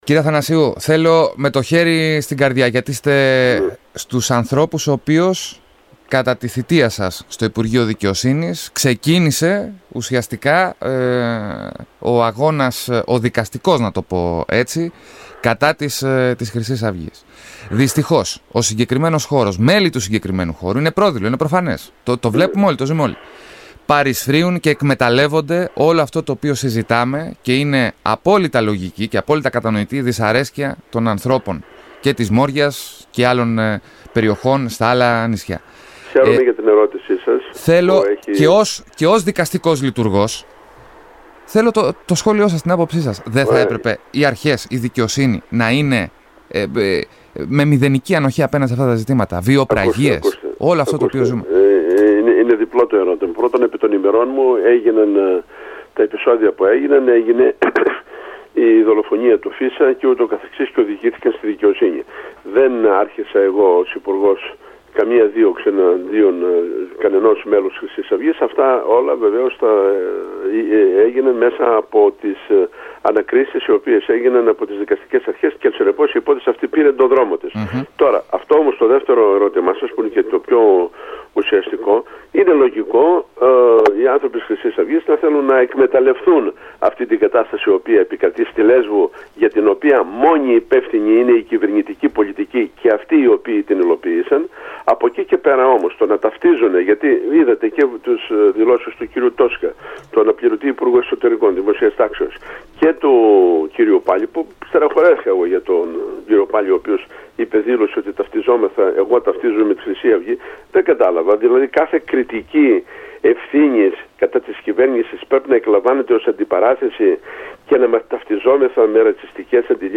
Ευθύνες στην κυβέρνηση για την κατάσταση στη Λέσβο, αναφορικά με το προσφυγικό, αλλά και δριμύ κατηγορώ στη “Χρυσή Αυγή” για απόπειρα εκμετάλλευσης της κατάστασης εξαπέλυσε ο Βουλευτής Λέσβου της Νέας Δημοκρατίας Χαράλαμπος Αθανασίου, μιλώντας στην ΕΡΑ ΑΙΓΑΙΟΥ.